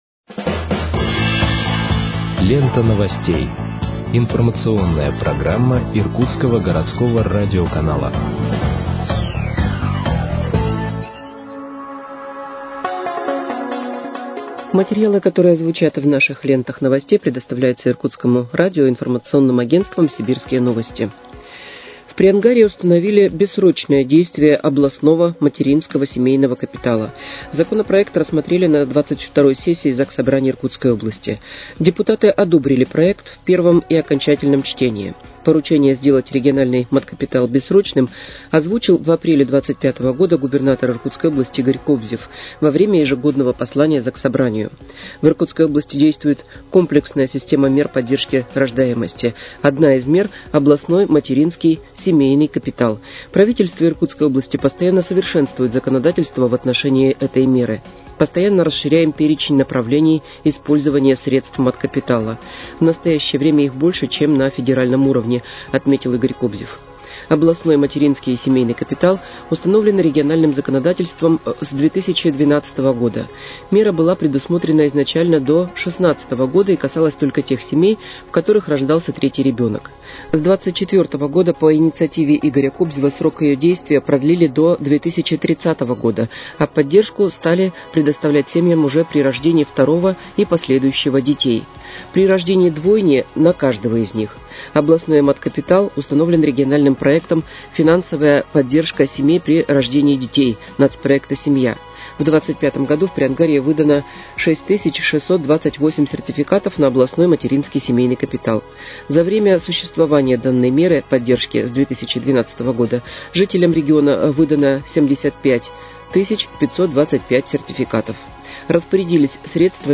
Выпуск новостей в подкастах газеты «Иркутск» от 22.09.2025 № 1